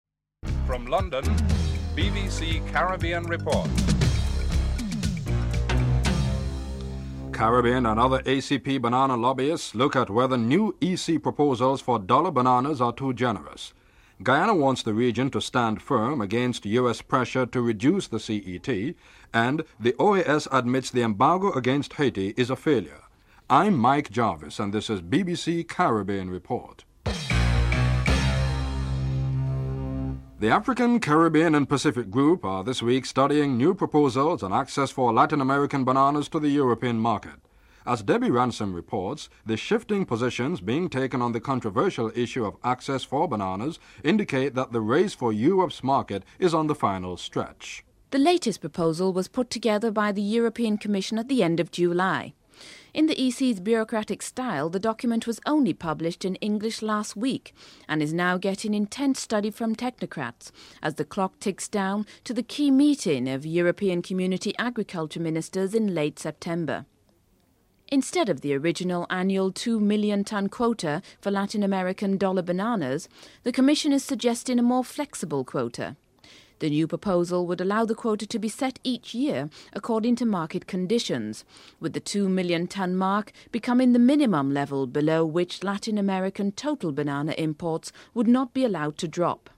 1. Headlines (00:00-00:29)
6. Secretary General of the OAS, João Baena Soares acknowledges that the international embargo against Haiti has failed (14:28-14:44)